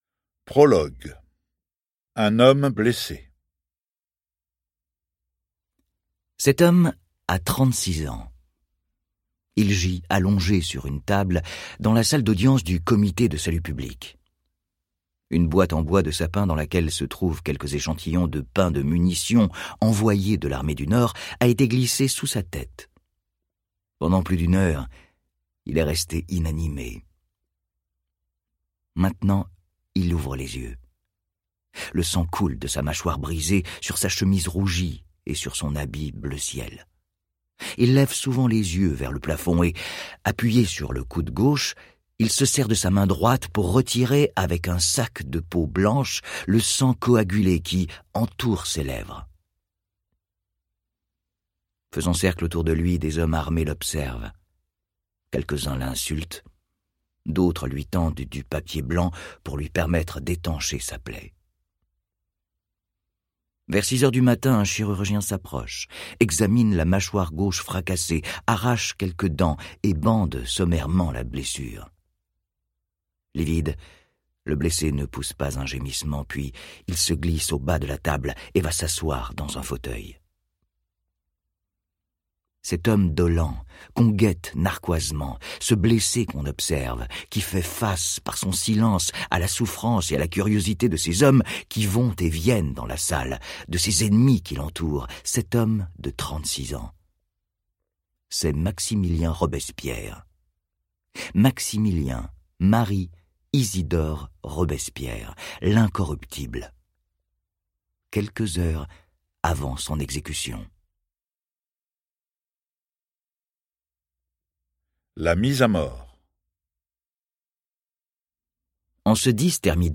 Diffusion distribution ebook et livre audio - Catalogue livres numériques
Lire un extrait - L'homme Robespierre, histoire d'une solitude de Max GALLO